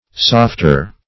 (s[o^]ft"[~e]r); superl.